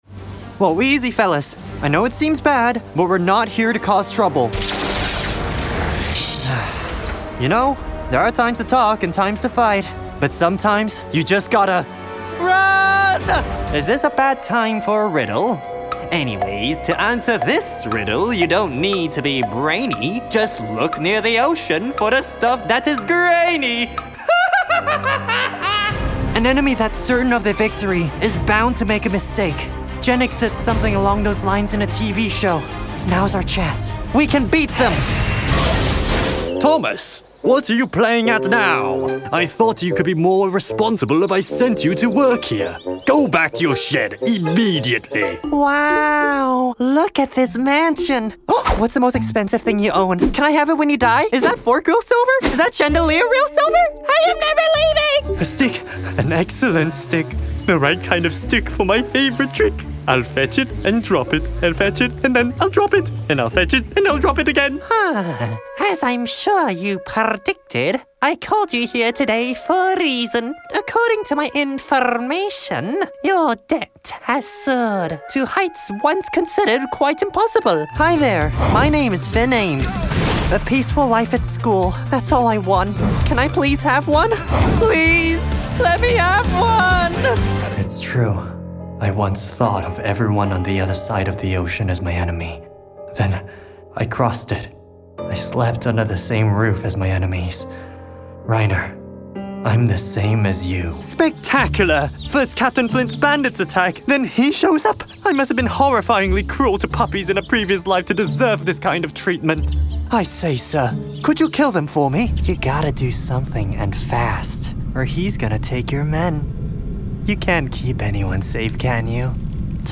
Animation